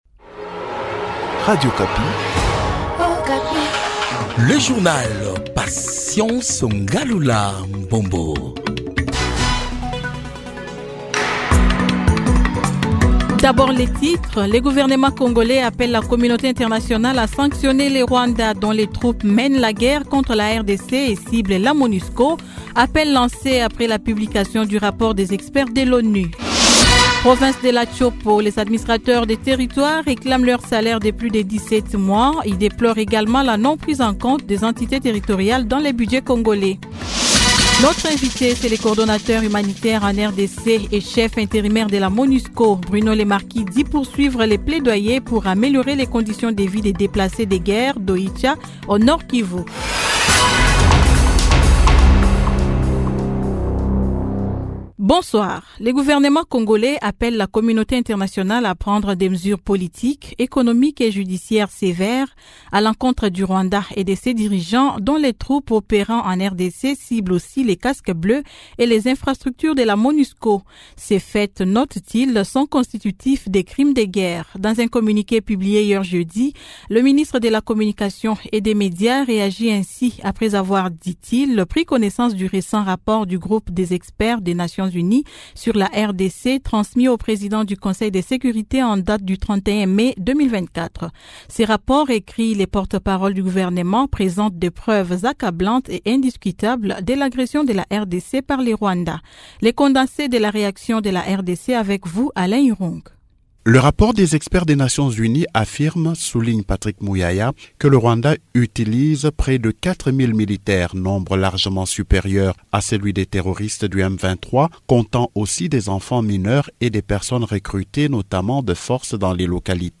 Journal Soir 18H